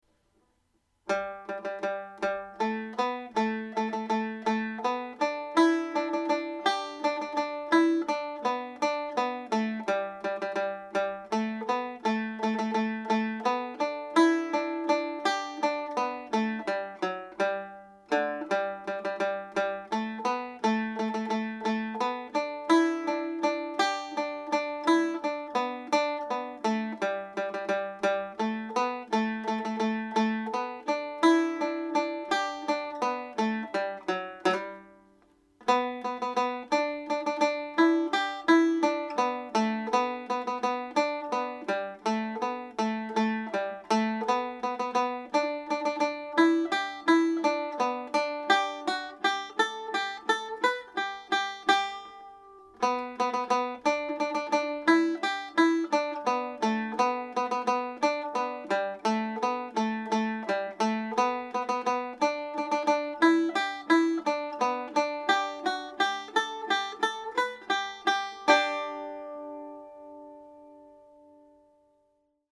full tune played with triplets
Kesh-Jig_triplets.mp3